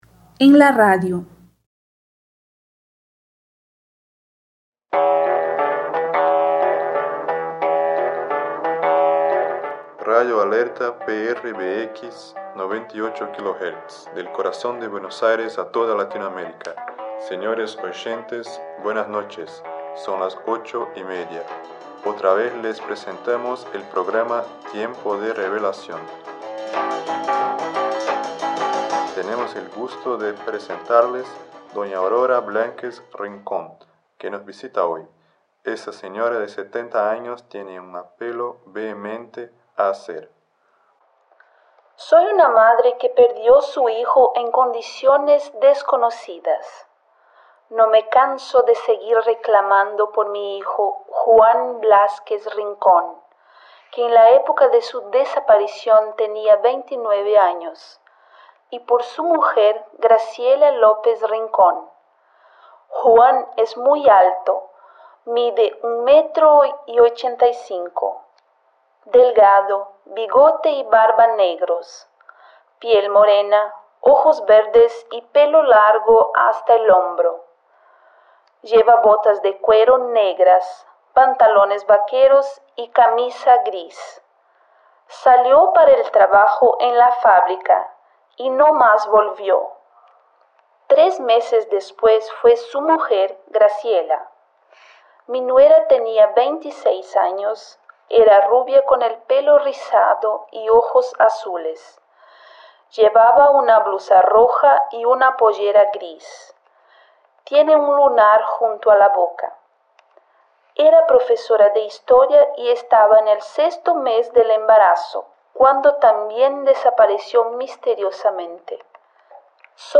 Diálogo VI: Na rádio